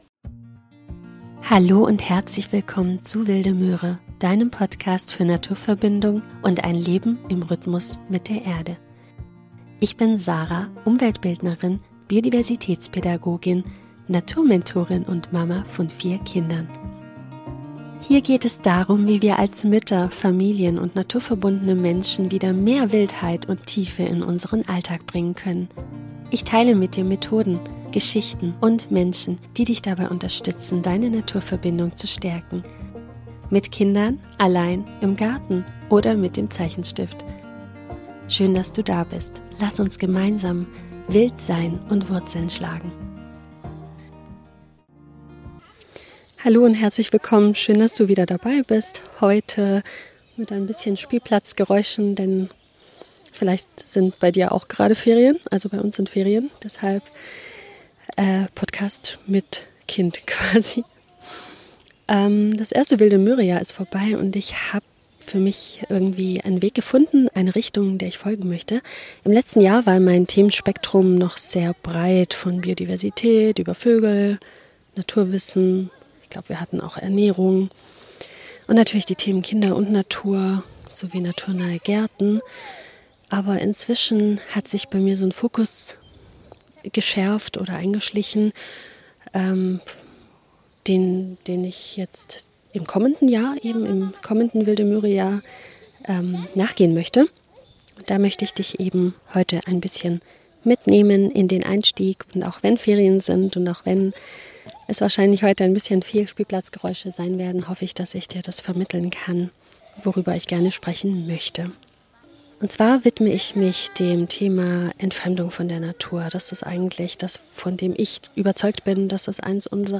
Beschreibung vor 9 Monaten ACHTUNG!!! ich sage an 2-3 Stellen Brennnessel, meine aber natürlich den Löwenzahn. vor lauter Spielplatz und Kindern war ein Versprecher wohl unvermeidbar. In dieser Folge nehme ich dich mit und erzähle dir, wie wir als Familie fünf einfache Wildpflanzen nutzen, um Naturverbindung im Alltag zu leben. Du bekommst Tipps zur Anwendung, persönliche Erfahrungen und ein DIY-Rezept mit der Schafgarbe.